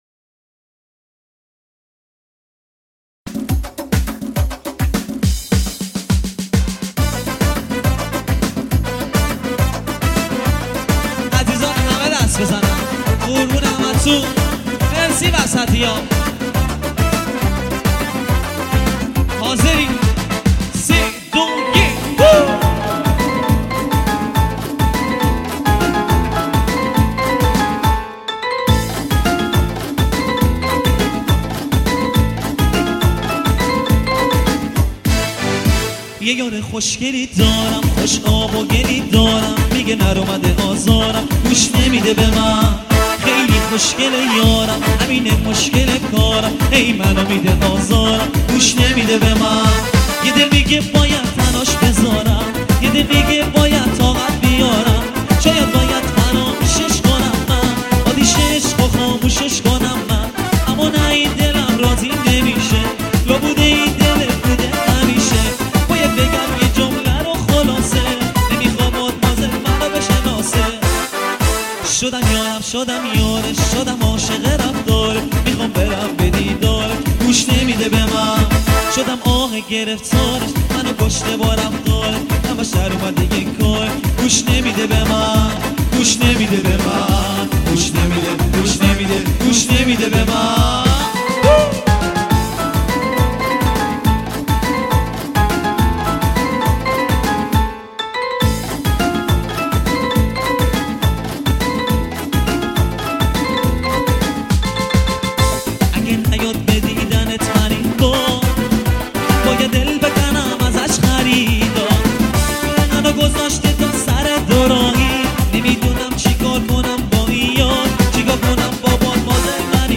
ریمیکس شاد فارسی جدید و فوق العاده زیبا
مخصوص مجالس عروسی